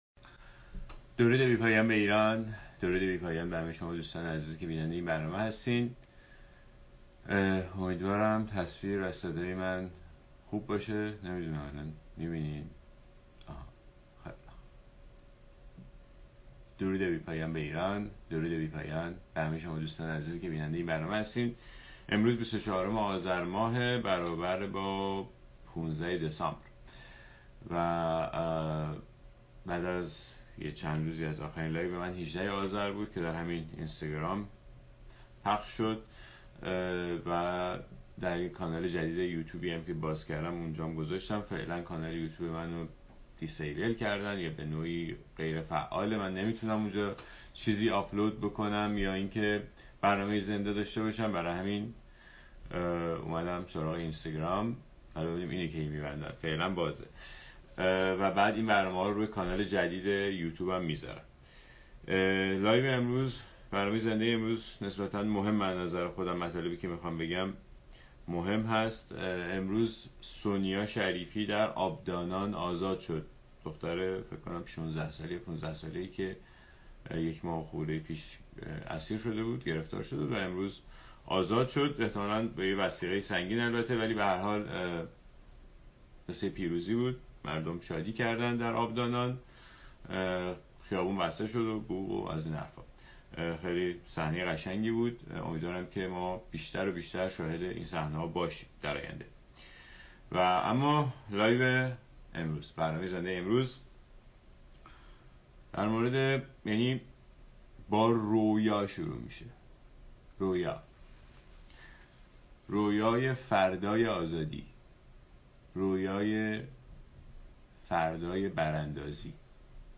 برنامه زنده ۲۴ آذر رويا، اميد، زندگی